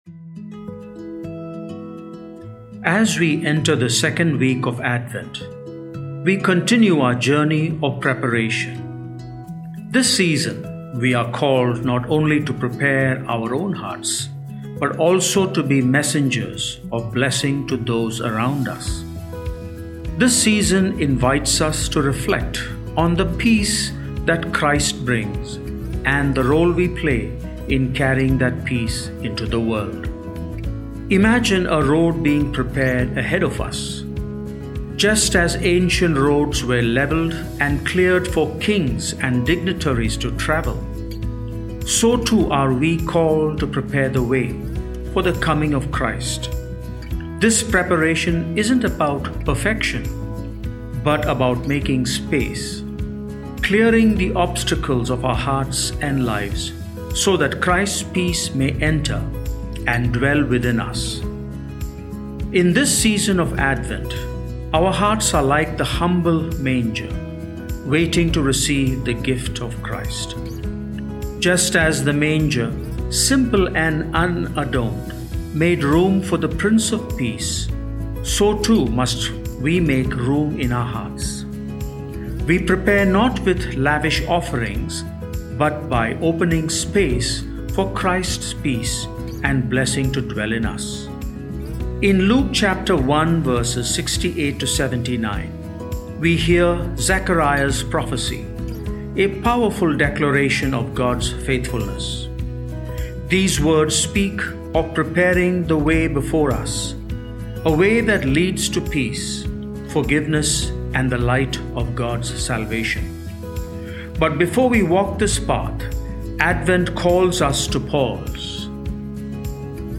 Sermon for December 8, 2024 – Second Sunday of Advent